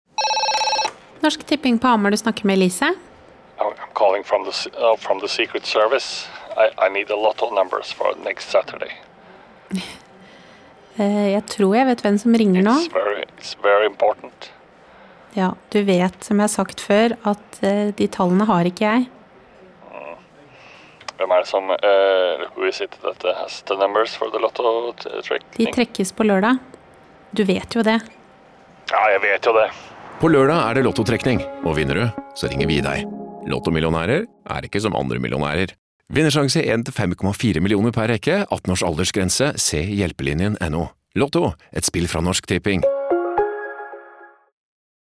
Regi må trekkes frem her og spesielt karakteren Elise er en favoritt: den stadige balanseringen mellom høflig og irritert er nydelig utført.